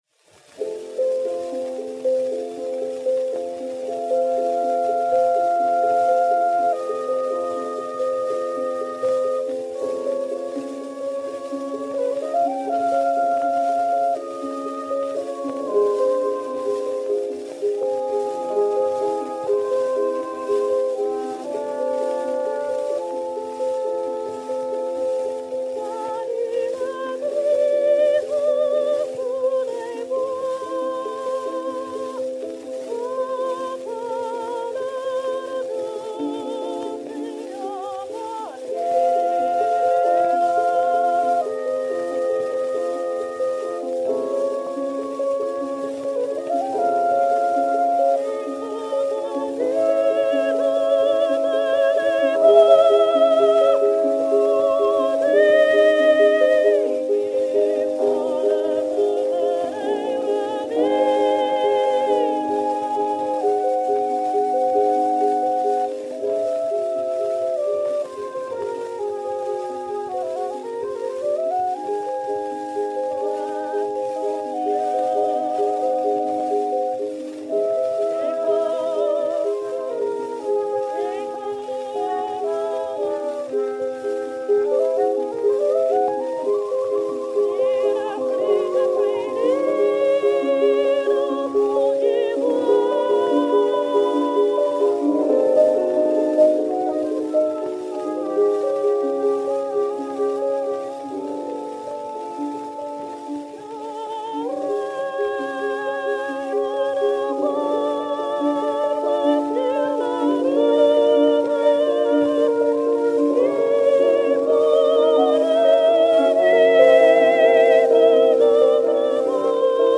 mélodie, extrait de Chansons lointaines
(par. André Lebey / mus. Georges Hüe)
flûte
Disque Pour Gramophone, inédit, mat. 4209 f, enr. à Londres le 19 mai 1910